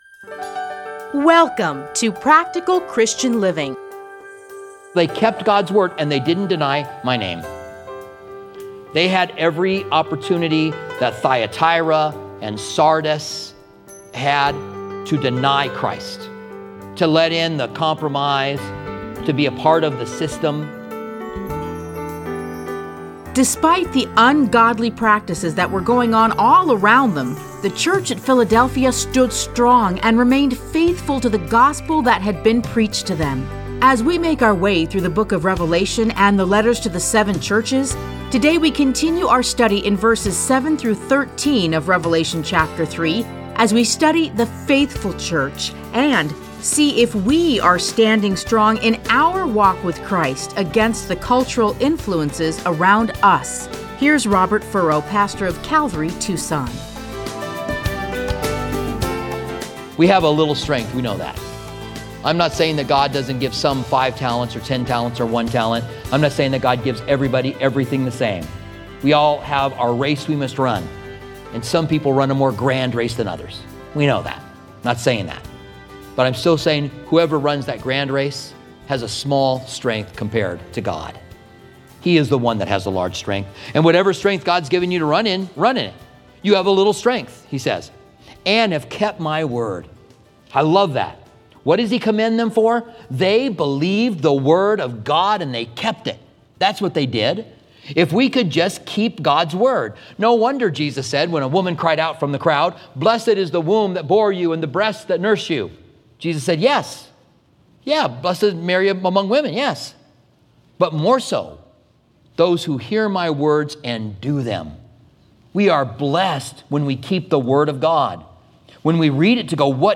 Listen to a teaching from Revelation 3:7-13.